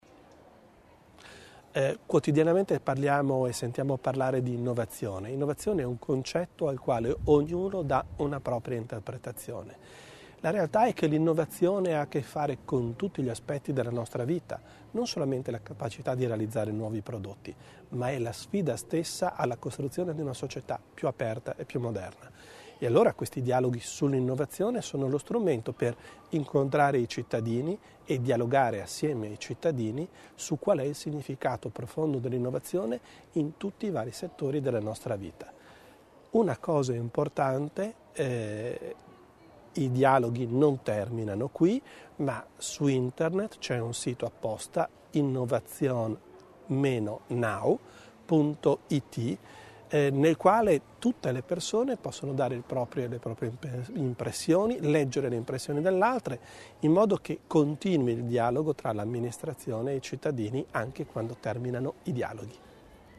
L'Assessore Bizzo sull'importanza dei dialoghi sull'innovazione